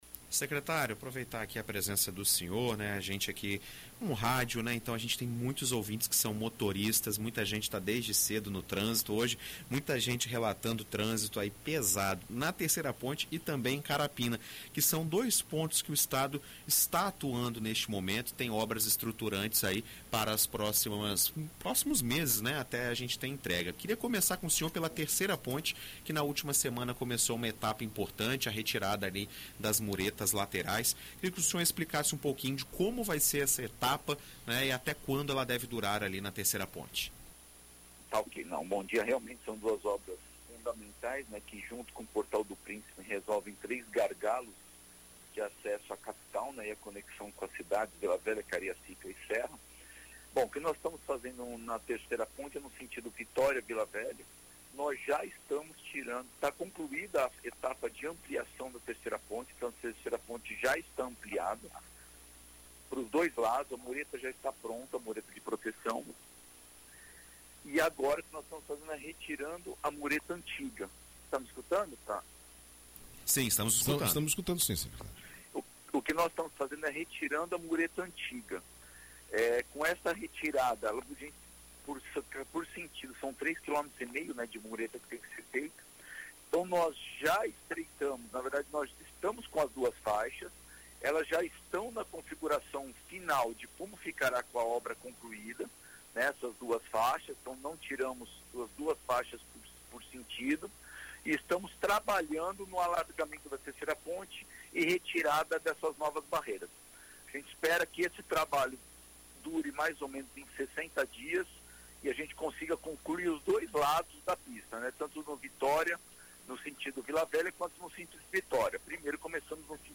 Em entrevista à BandNews FM ES nesta segunda-feira (06), o Secretário de Mobilidade e Infraestrutura, Fábio Damasceno, fala sobre o funcionamento e os prazos para as obras da Terceira Ponte.